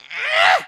horror
Witch Grunt